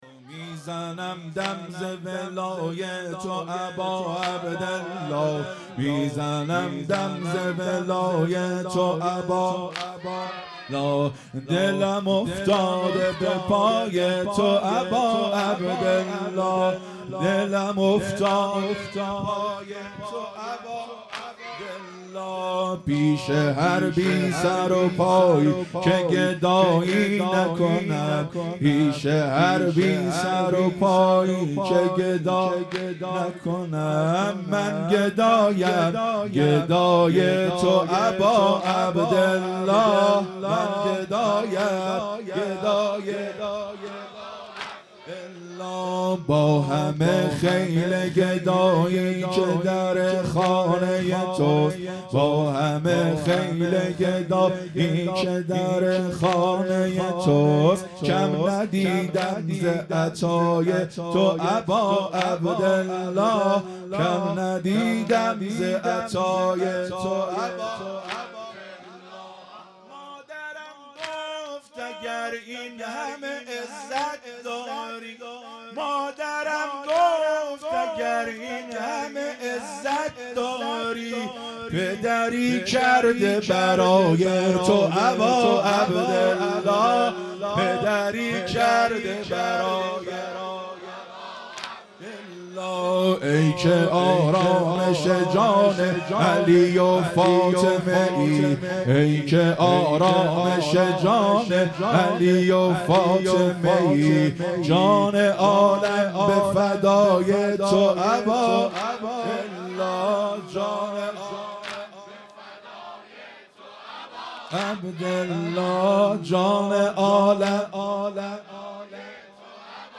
هیئت مکتب الزهرا(س)دارالعباده یزد
واحد تند| میزنم دم ز ولای تو اباعبدالله
محرم ۱۴۴۵_شب ششم